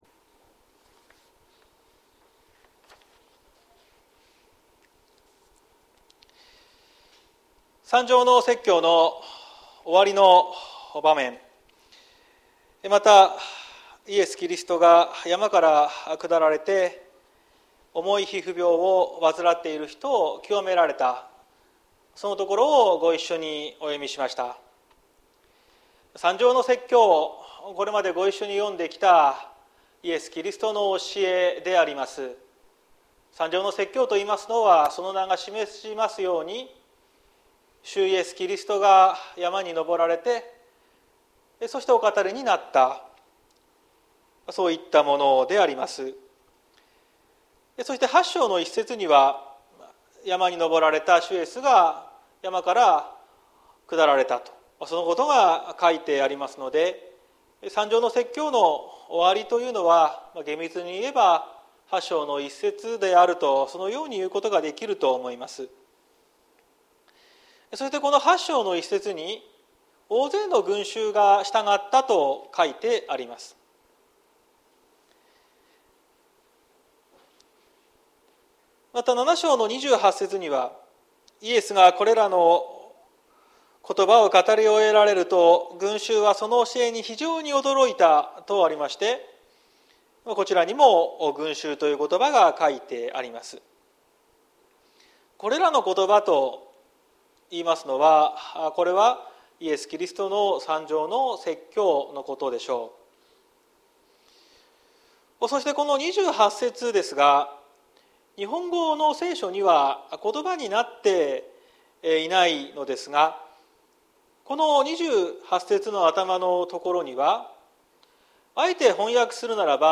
2023年06月04日朝の礼拝「キリストの権威に従う」綱島教会
説教アーカイブ。